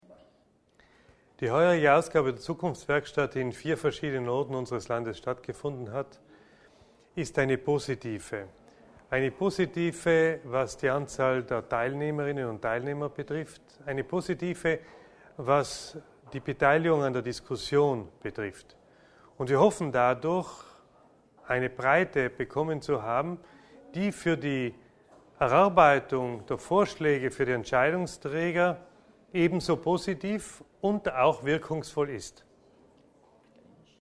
INTV_MICHL EBNER_Zukunftswerkstatt_DE_64K.mp3